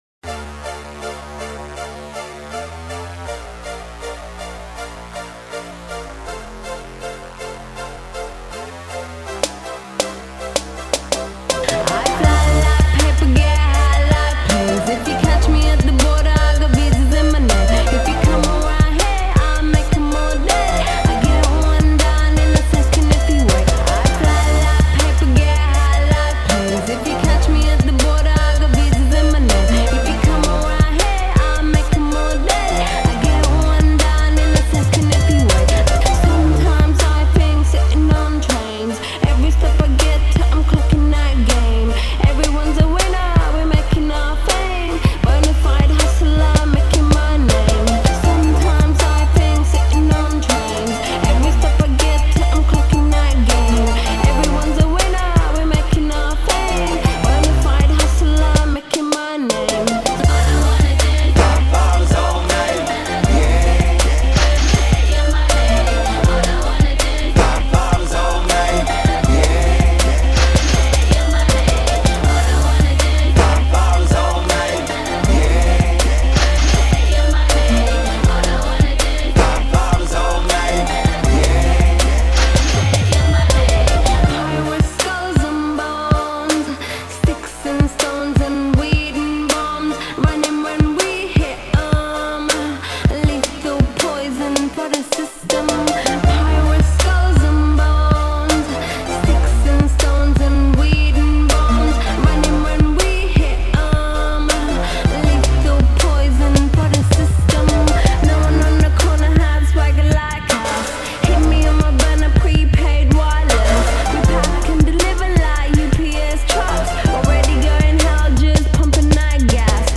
This is just an instrumental and an a capella track.
I spent a really long time looking for the champagne sound.
Filed under: Mashup | Comments (2)